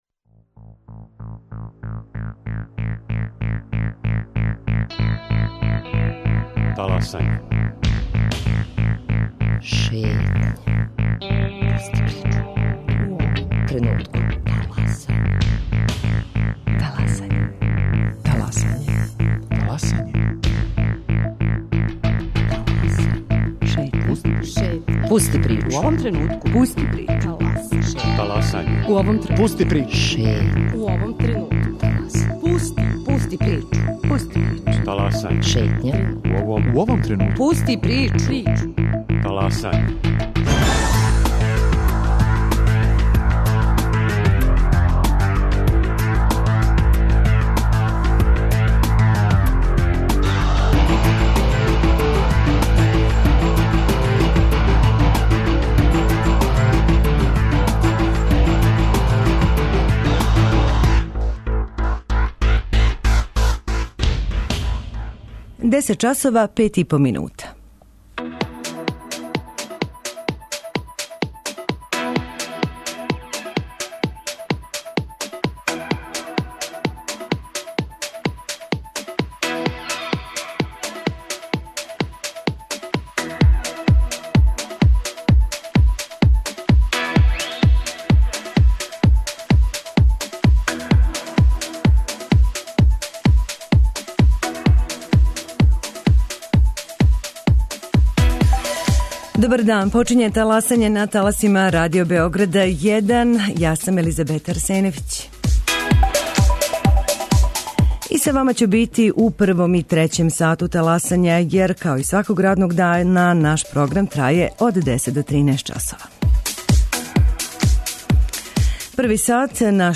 у телефонском интервјуу